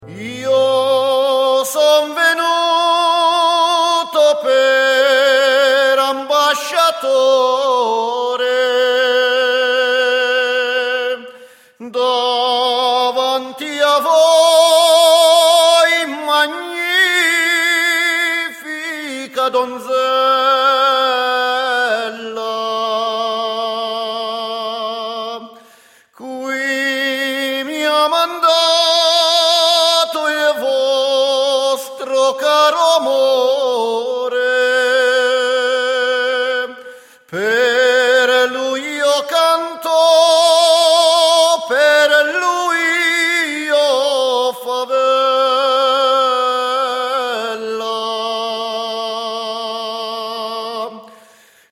Dalle musiche da ballo della tradizione popolare emiliana,
DEMO mp3 - Frammenti brani registrazione live